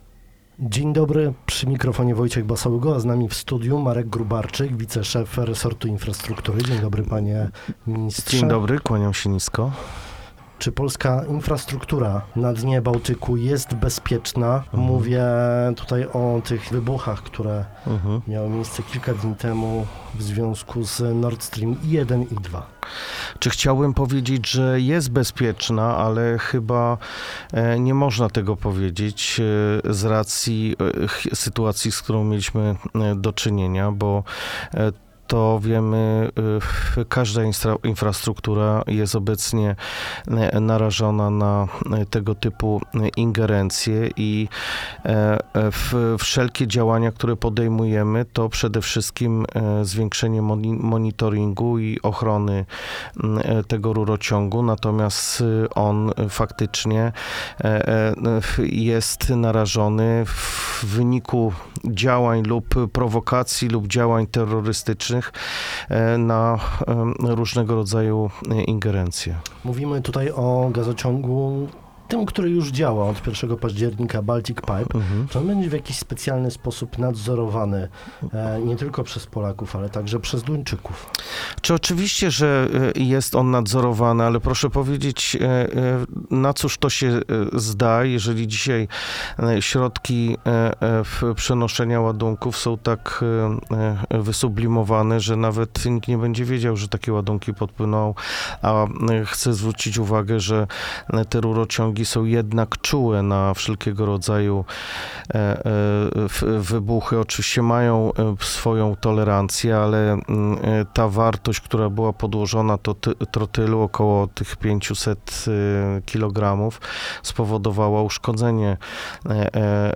Dziś naszym gościem Rozmowy Dnia był Marek Gróbarczyk, sekretarz stanu, pełnomocnik rządu ds. gospodarki wodą oraz inwestycji w gospodarce morskiej i wodnej. Czy polska infrastruktura na dnie Bałtyku jest bezpieczna w związku z wybuchami, które miały miejsce kilka dni temu?